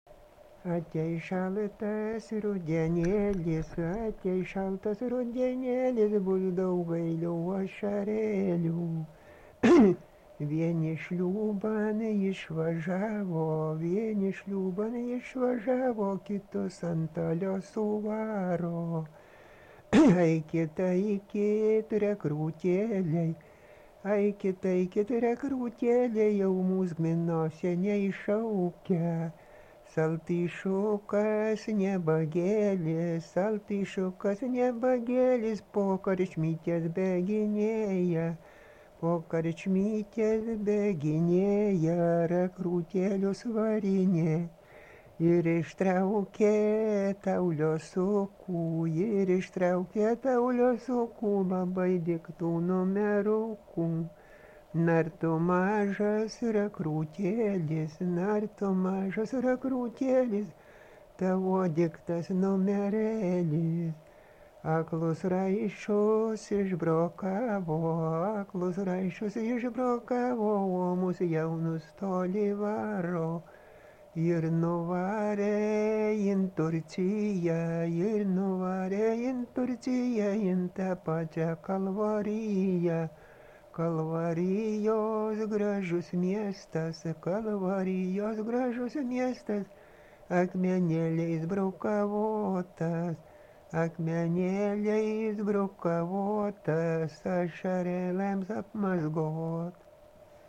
Dalykas, tema daina
Erdvinė aprėptis Valančiūnai
Atlikimo pubūdis vokalinis